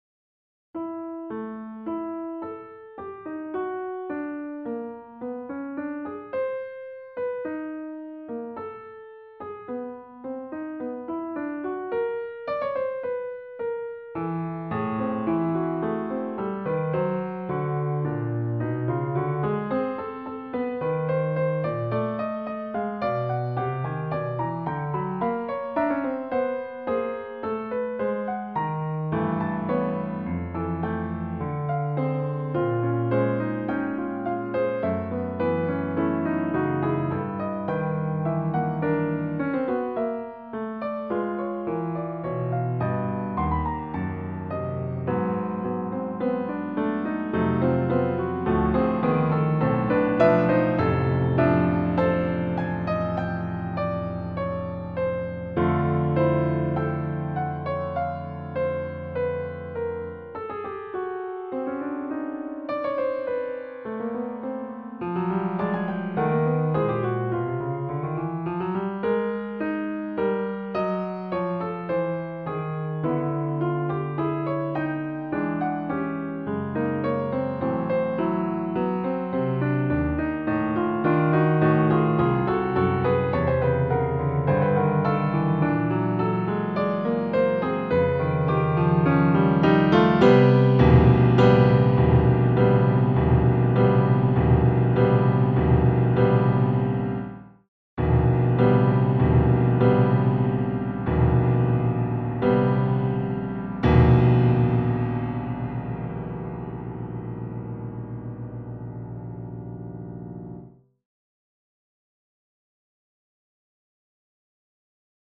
Compo 2 is just a piano solo which doesn't require much mixing.